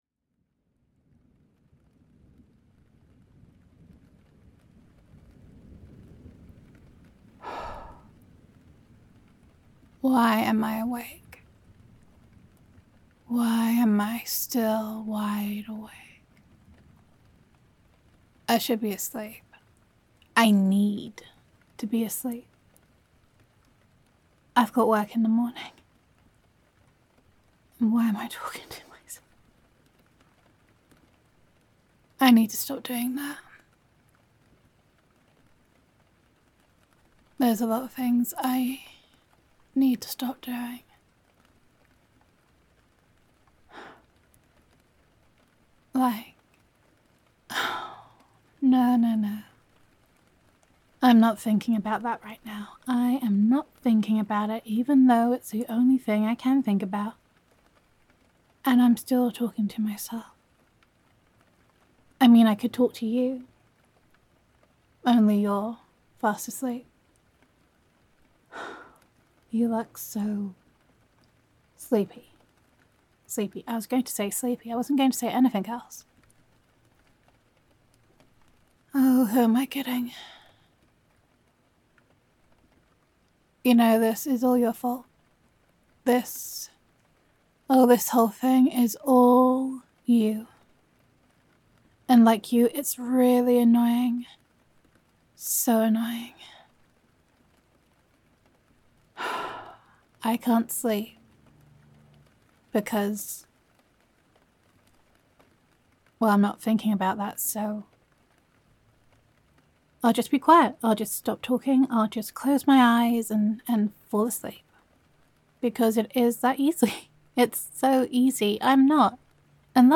[F4A] Why Am I Still Wide Awake [Girlfriend Roleplay]